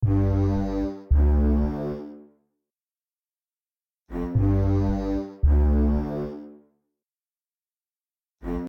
描述：Logic Pro 9合成器循环。
标签： 111 bpm Hip Hop Loops Synth Loops 1.46 MB wav Key : Unknown
声道立体声